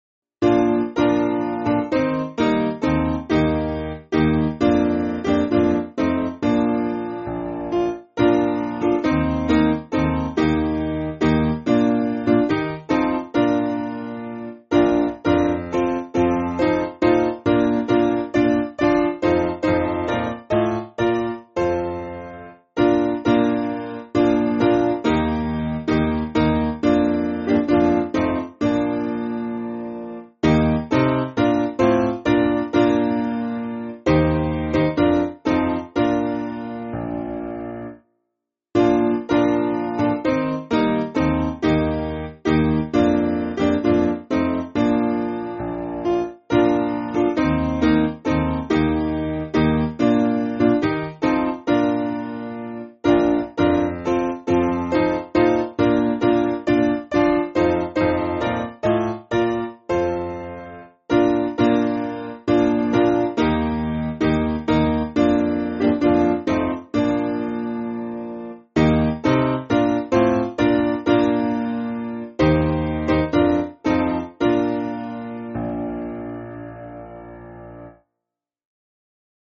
Simple Piano
(CM)   2/Bb